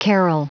Prononciation du mot carol en anglais (fichier audio)
Prononciation du mot : carol